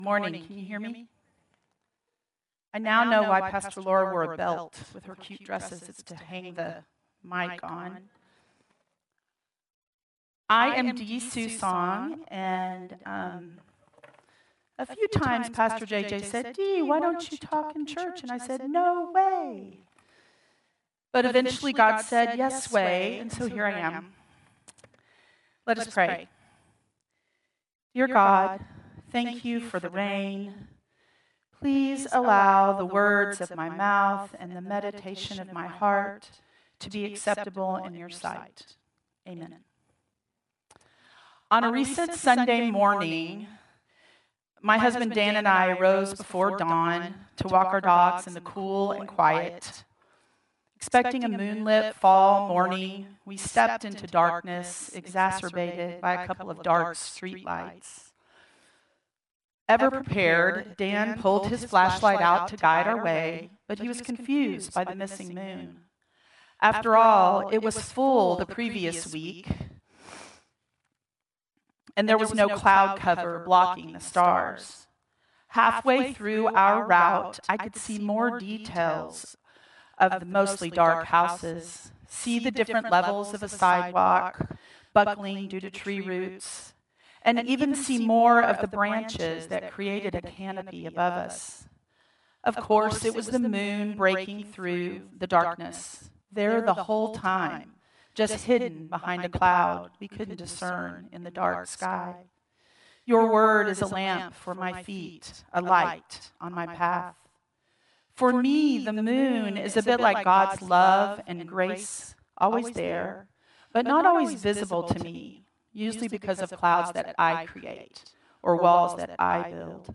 Contemporary Service 10/26/2025